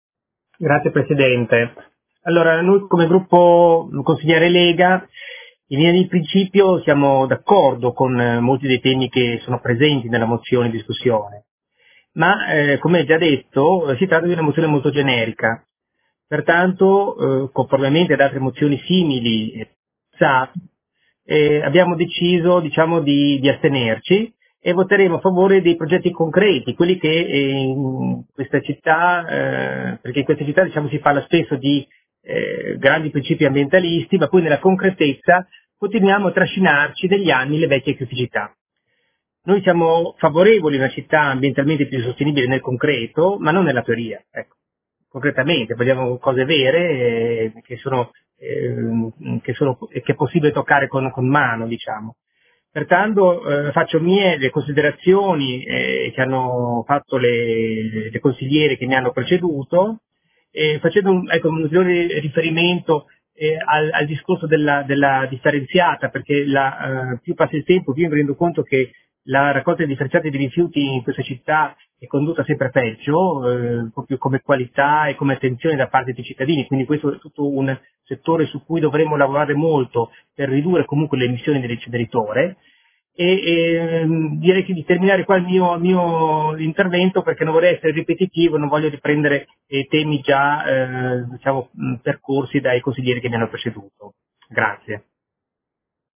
SEDUTA DEL 13/05/2020. DIBATTITO SU MOZIONE PROT. GEN. 124956
Audio Consiglio Comunale